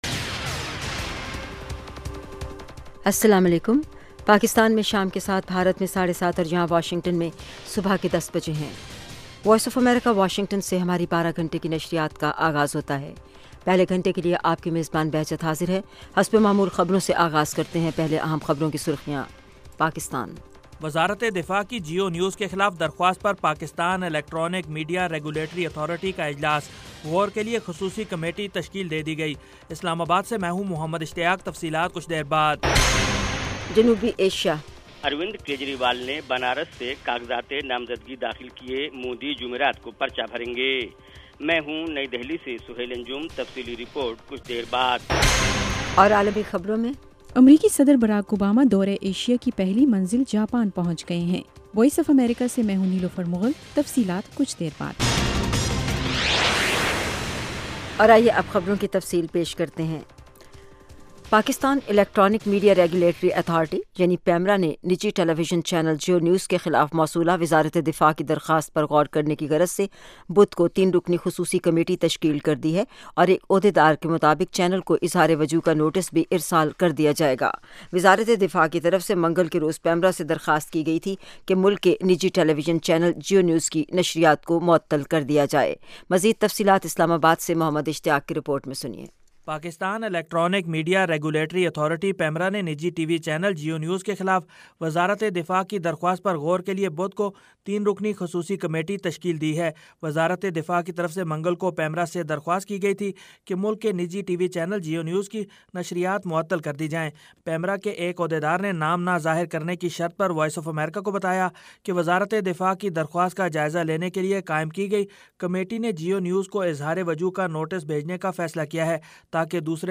7:00PM اردو نیوز شو اس ایک گھنٹے میں دن بھر کی اہم خبریں اور پاکستان اور بھارت سے ہمارے نمائندوں کی روپورٹیں پیش کی جاتی ہیں۔ اس کے علاوہ انٹرویو، صحت، ادب و فن، کھیل، سائنس اور ٹیکنالوجی اور دوسرے موضوعات کا احاطہ کیا جاتا ہے۔